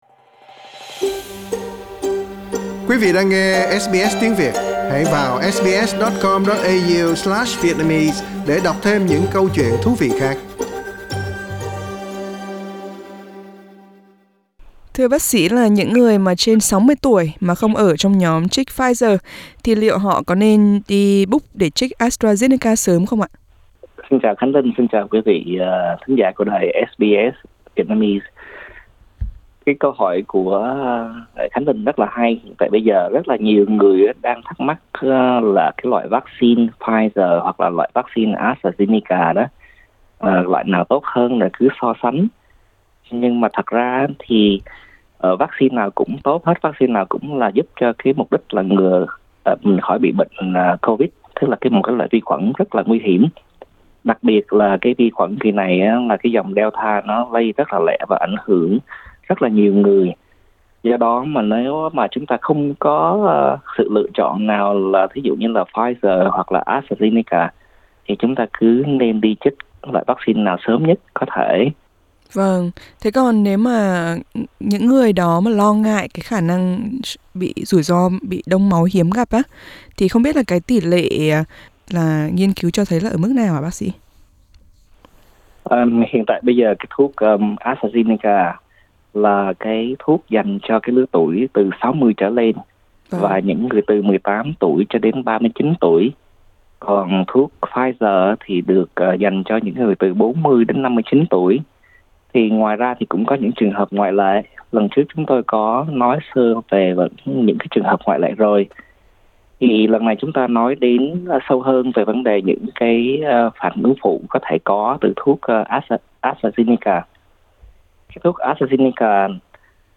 SBS trò chuyện với bác sỹ